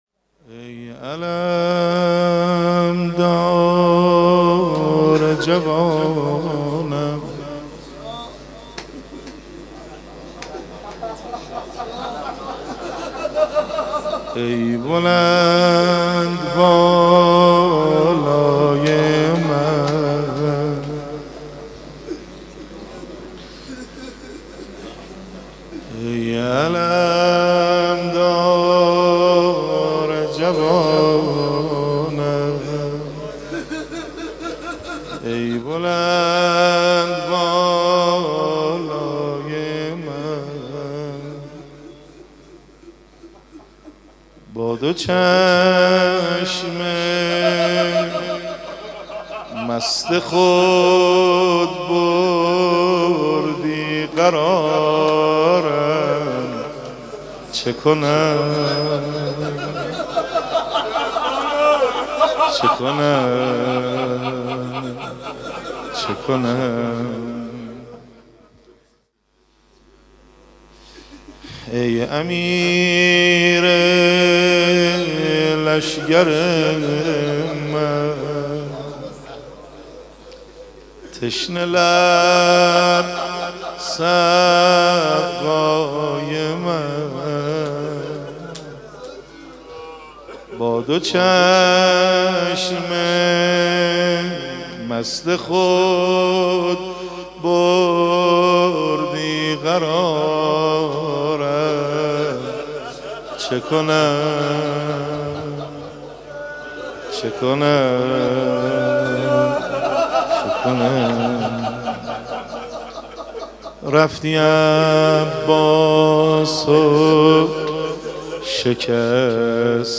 قالب : روضه